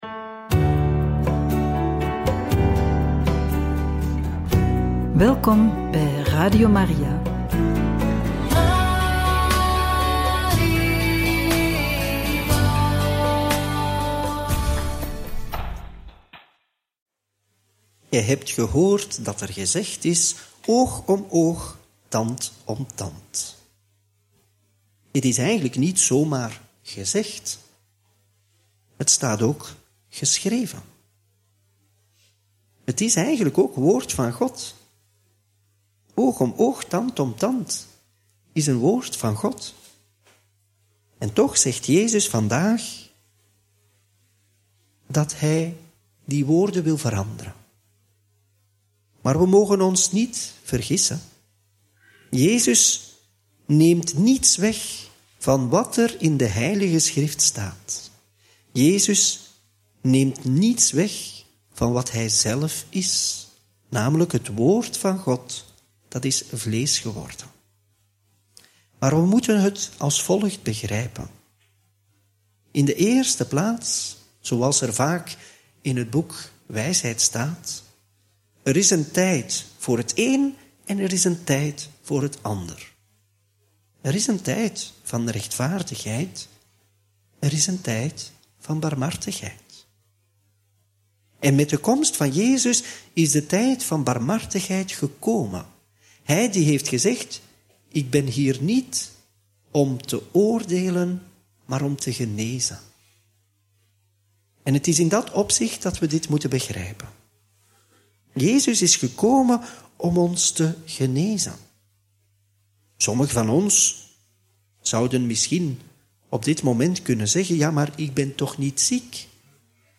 Homilie bij het Evangelie van maandag 17 juni 2024 (Mt 5, 38-42)